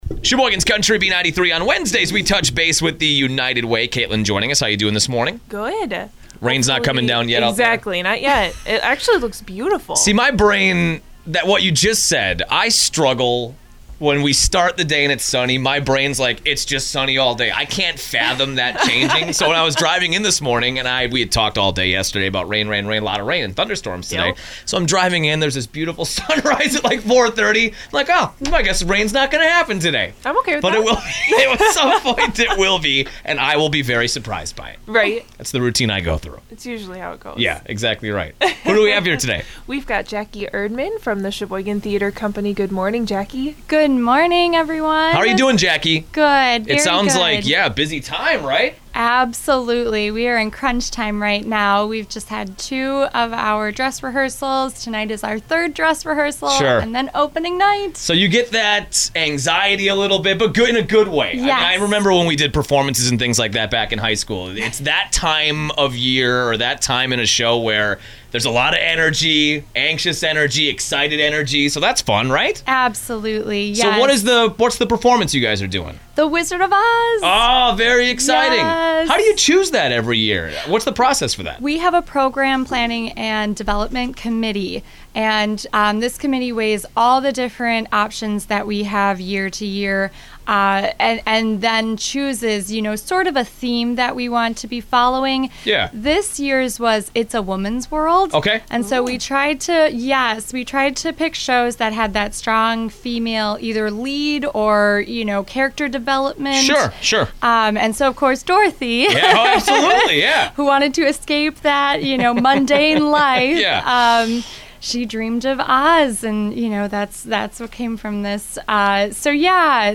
Sheboygan Theatre Company on the Radio this week!